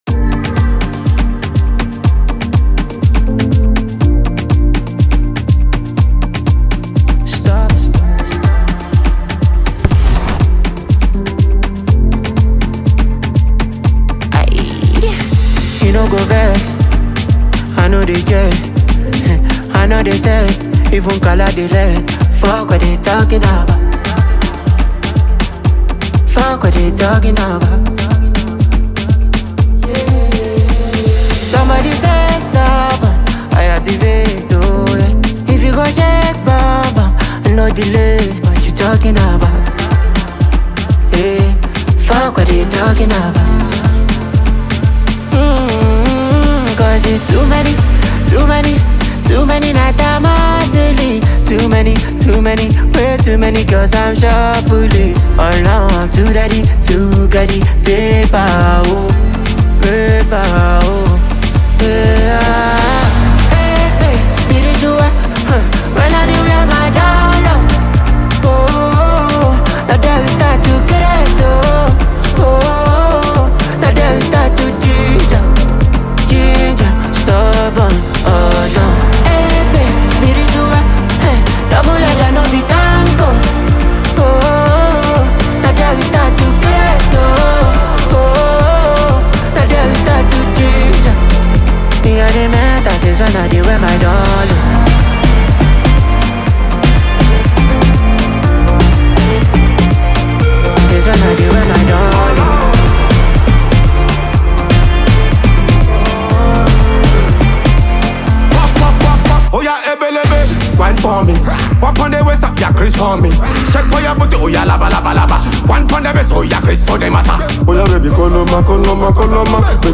hot House-infused dance banger